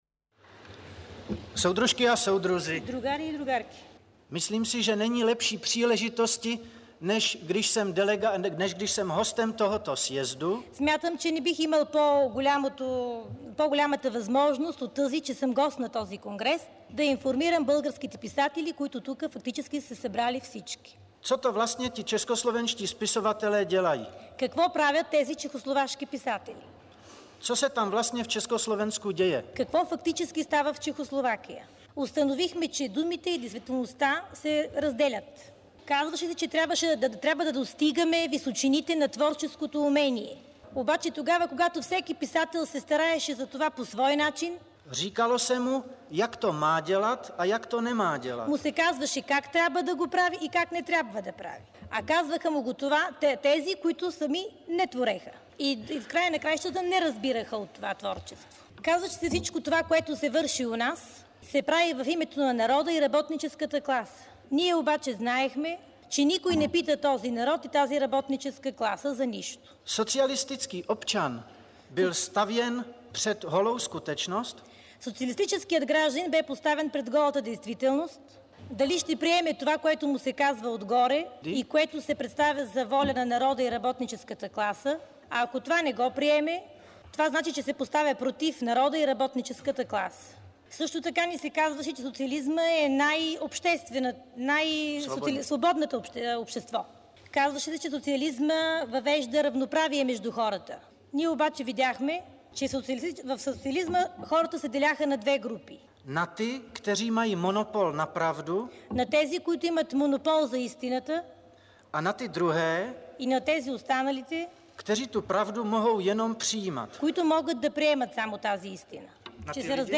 част от приветственото му слово към делегатите на първия конгрес на Съюза на българските писатели (СБП), проведен на 21 май 1968 година.